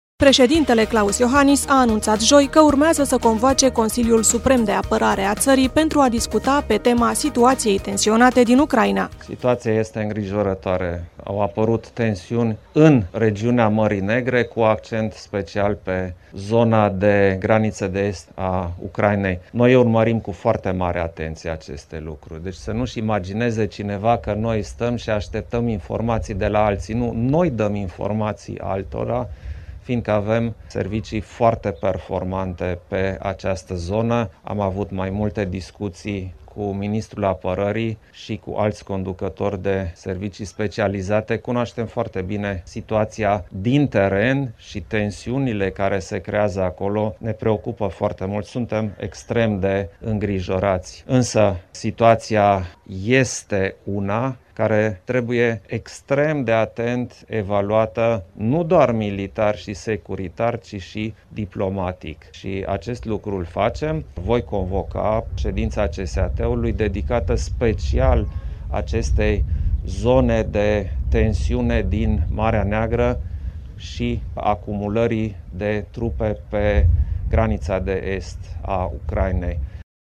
Declarație Klaus Iohannis despre “tensiunile din Marea Neagră”